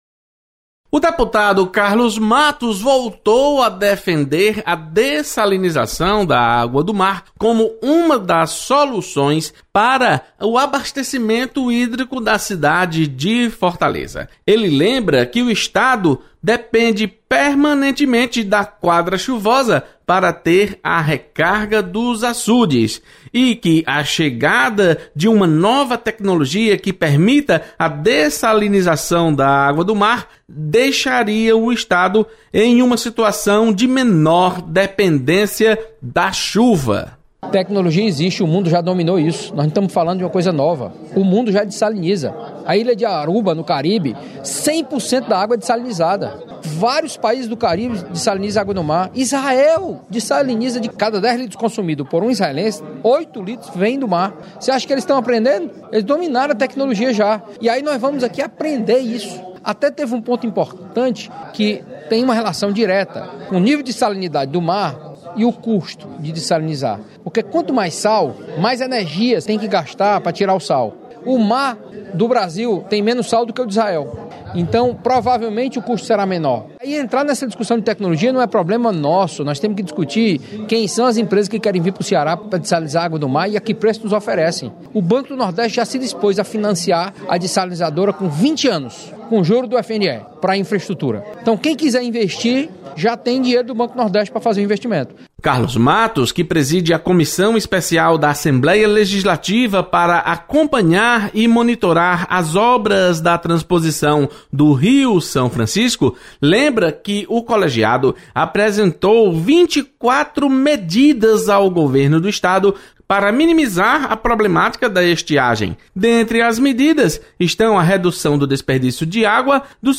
Plenário
Deputado Carlos Matos aponta a dessalinização da água do mar como essencial para o abastecimento de água em Fortaleza.